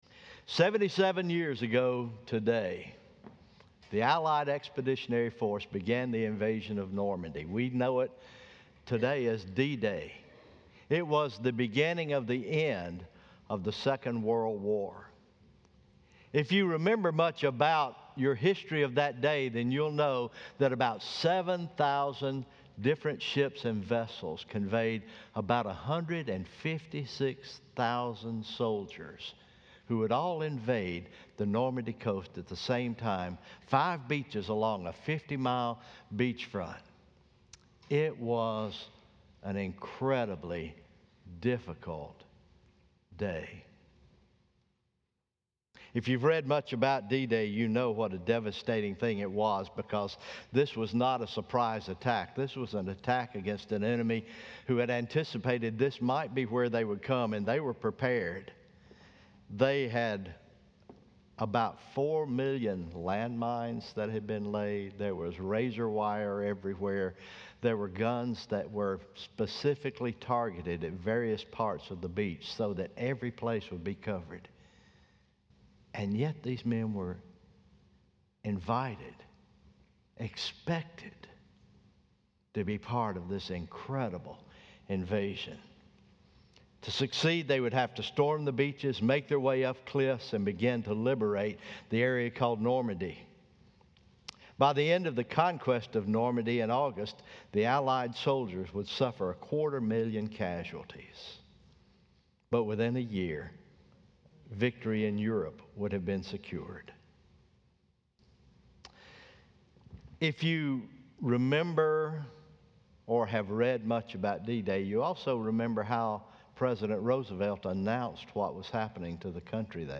Morning Worship Memorial Day Weekend